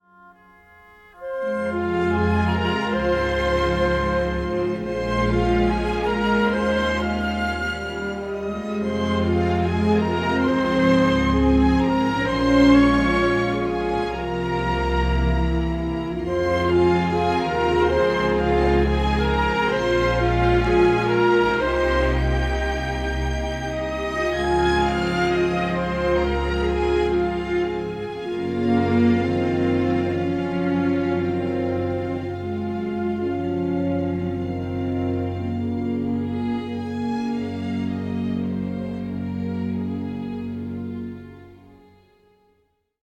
tuneful Americana